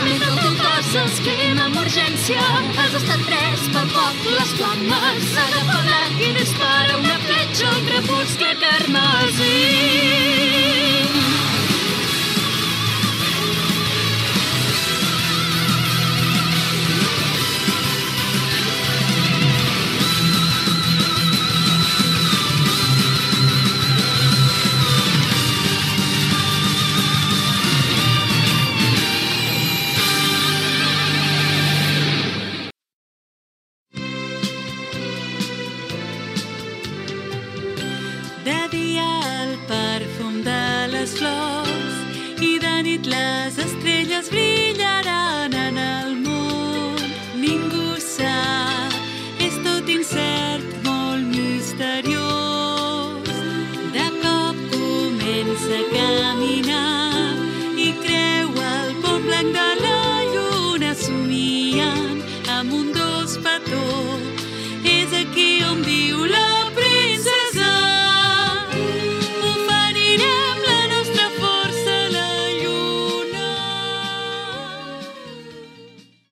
Temes musicals de sèries d'anime sense presentació